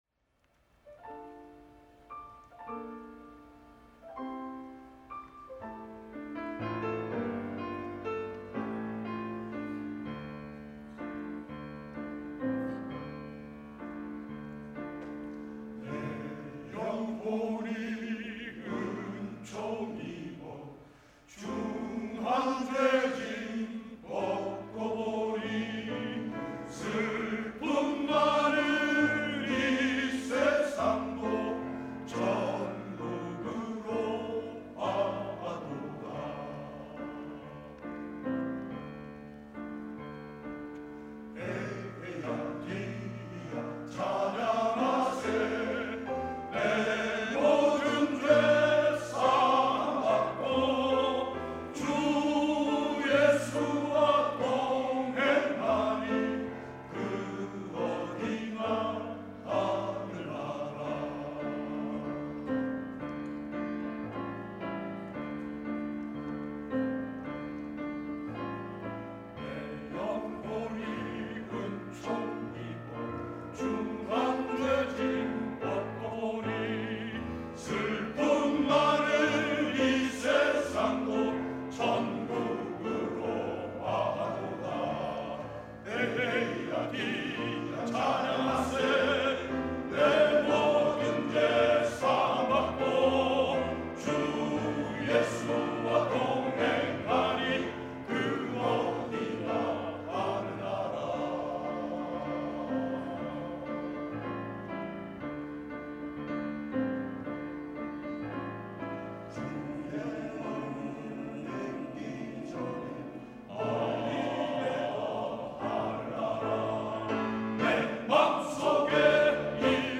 # 첨부 1.05 내 영혼이 은총 입어(갈렐남성합창단).mp3